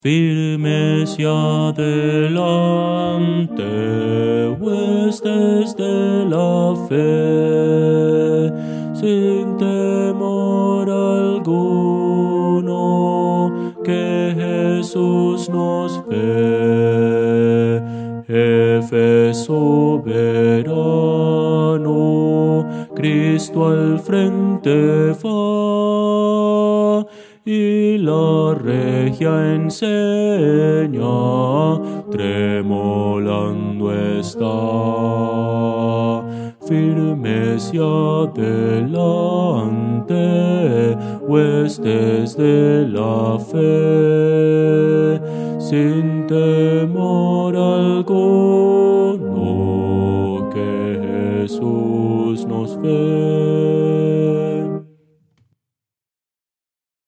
Voces para coro
Bajo – Descargar